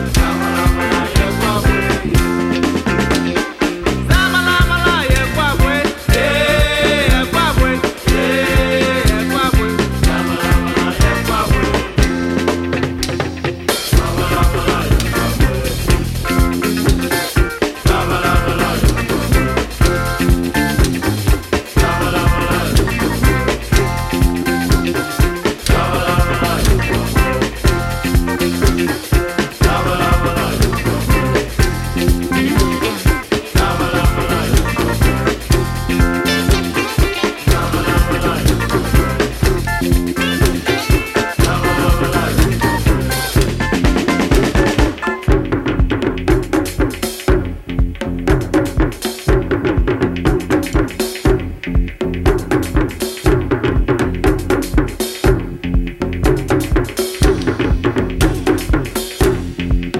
A surefire Afro-Funk classic
Ghanaian singing/percussion sensation